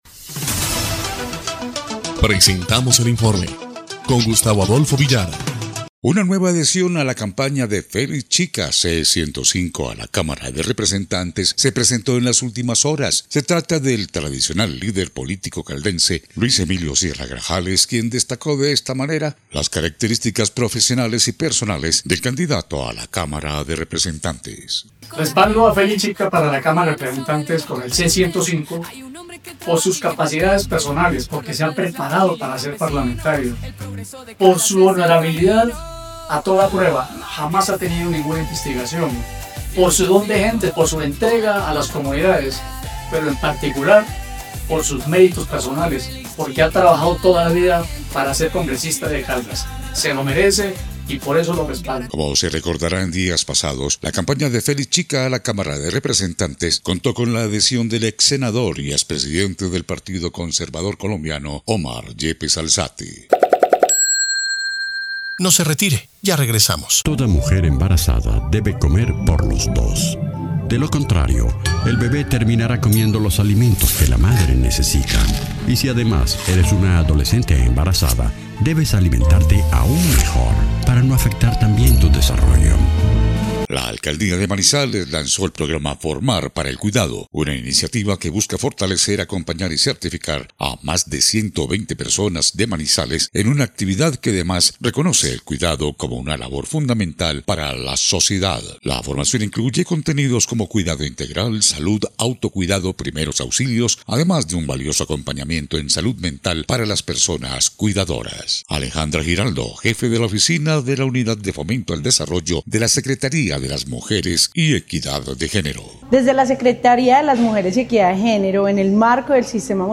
EL INFORME 1° Clip de Noticias del 4 de febrero de 2026